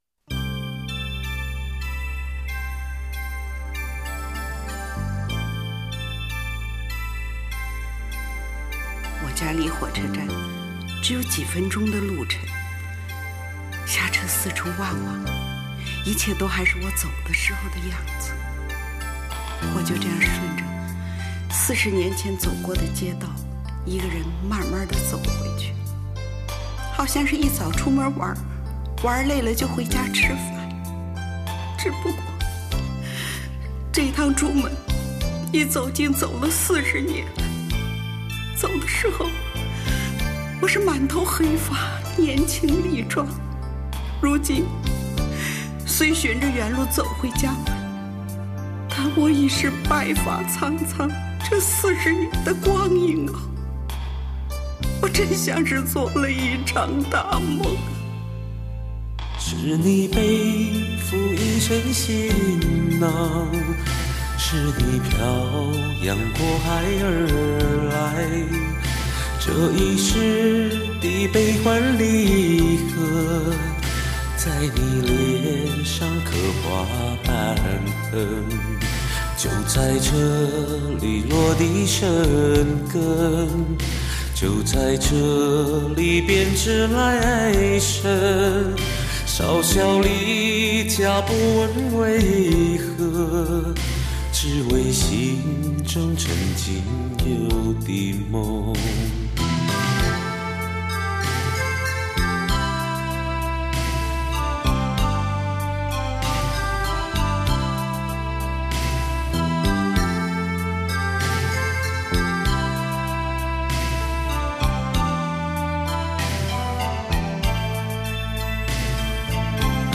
[13/6/2009] 一首特别感人凄凉的歌 真的很好听 进来听下吧！不经典不发的好歌